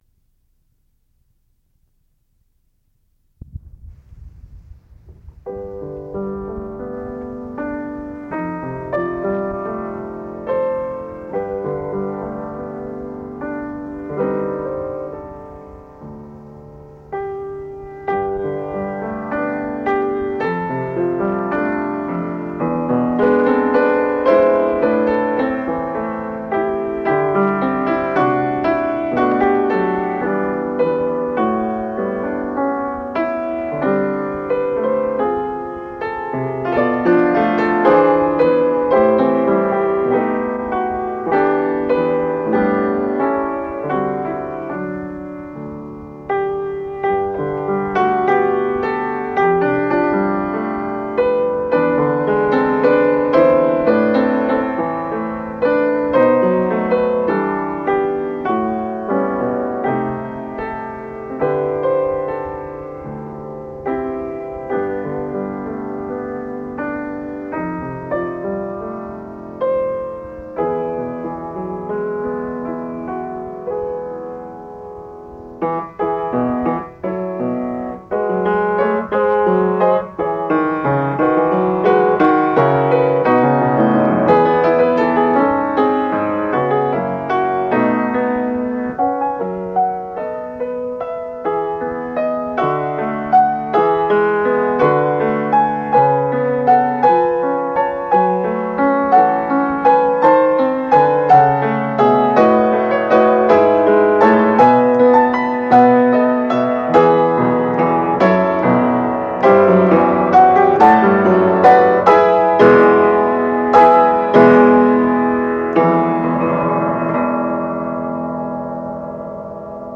Here are some of my favorite piano arrangements: O Come All Ye Faithful, Silent Night, While Shepherds Watched Their Flocks, Three Carols, Little Drummer Boy